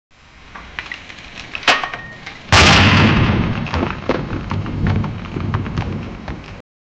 Crashing Sound Effects - Free AI Generator & Downloads | SFX Engine
crashing-sound-of-a-heavy-cyy7mdtv.wav